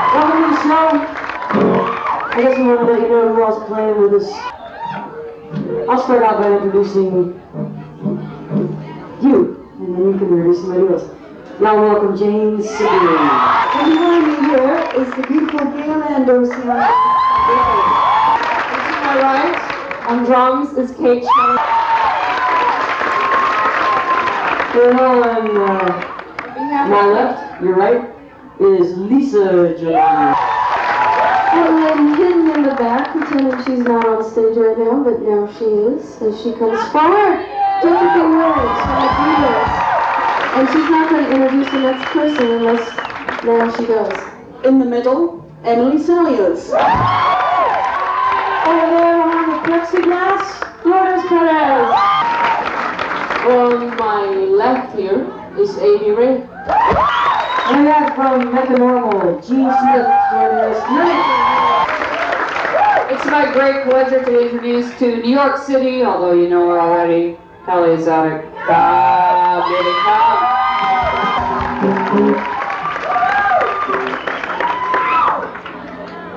lifeblood: bootlegs: 1998-09-03: irving plaza - new york, new york (suffragette sessions tour)
02. talking with the crowd (1:16)